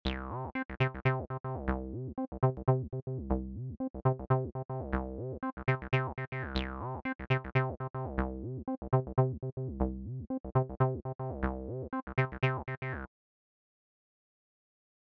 Automate for Evolving Soundscapes
Pro Tip: Try automating cutoff and resonance together—this creates a deeper, more complex acid sound, especially when paired with accents and slides.